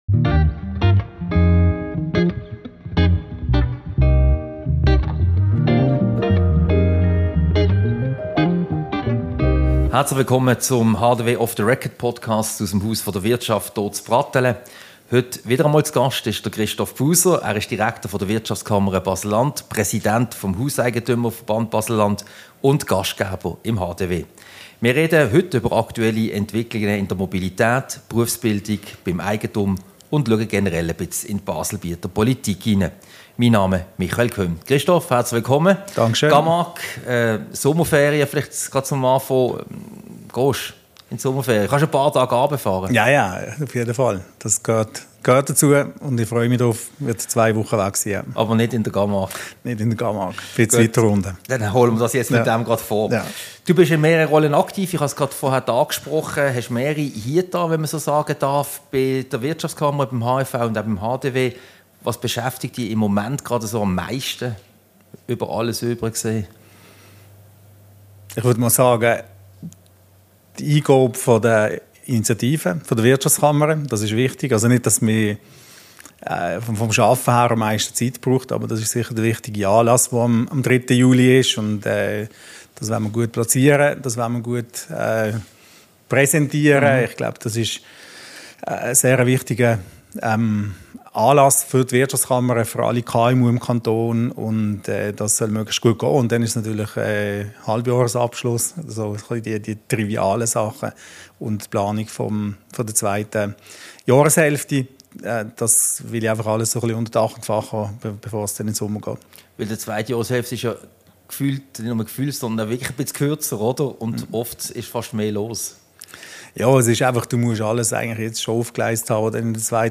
Diese Podcast-Ausgabe wurde im Raum Camargue im Haus der Wirtschaft HDW aufgezeichnet.